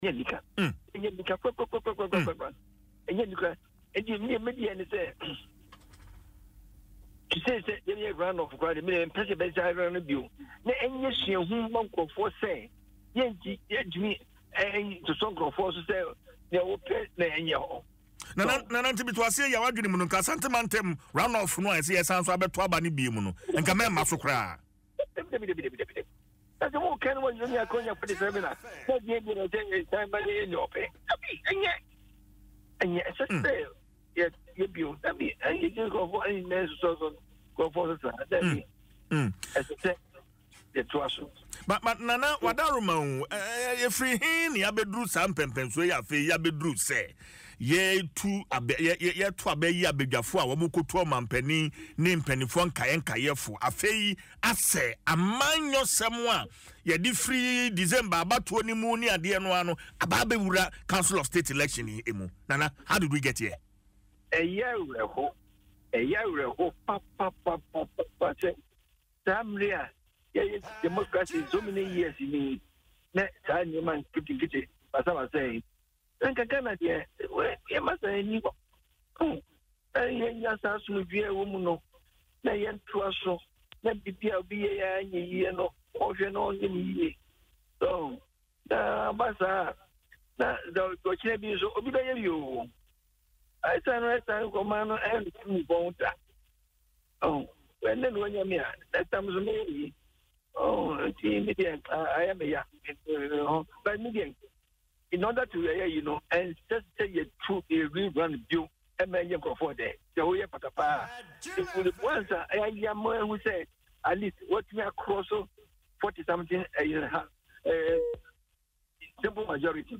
Speaking on Dwaso Nsem on Adom FM, Nana Somuah, who was present at the election, described the incident as unacceptable.